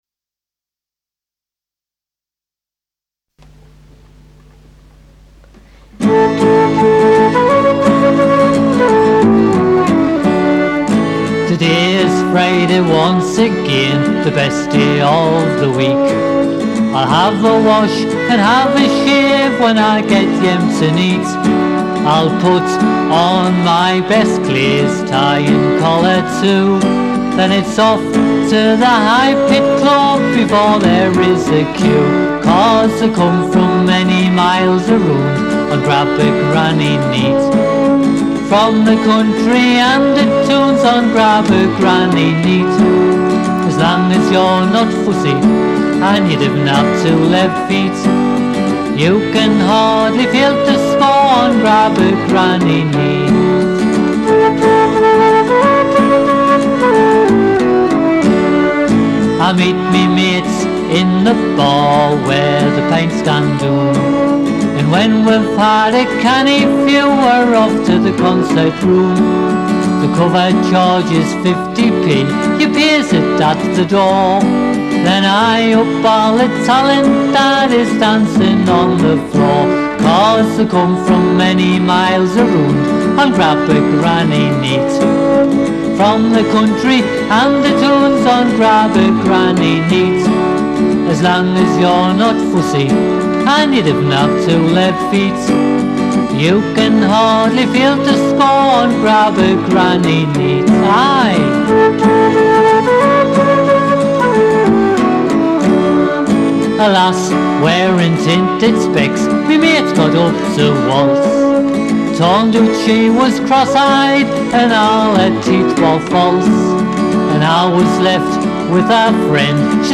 Flute
Fiddle
Guitar & Vocals